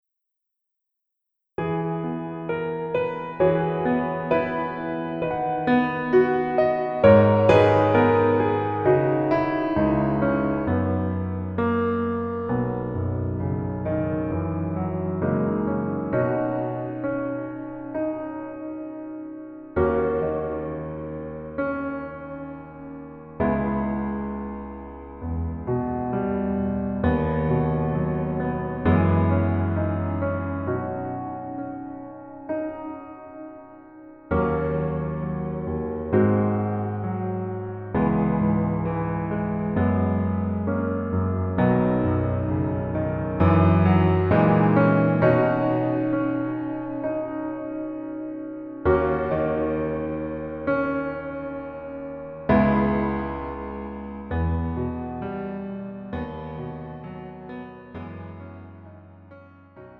음정 -3키
장르 가요 구분 Pro MR